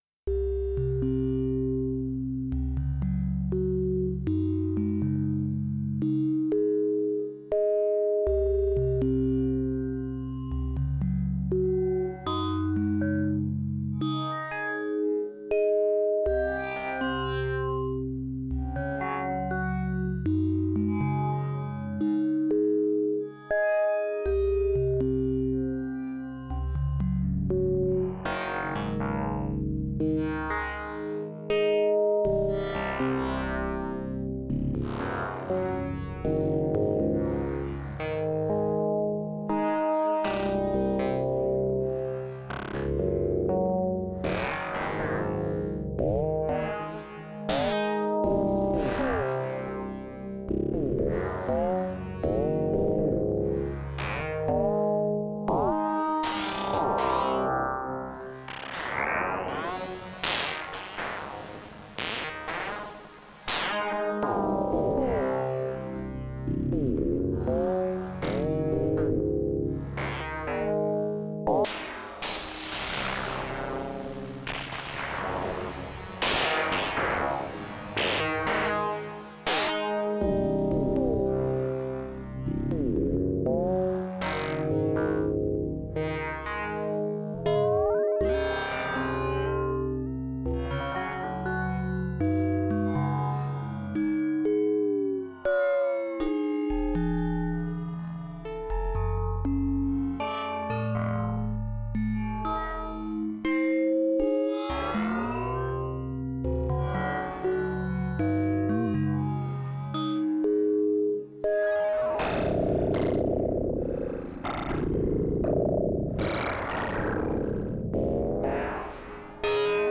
Just a simple sequence to test FM on wavetable. Its a single wavetable OSC frequency modulated by a sin . Just playing with the param while the sequence is playing.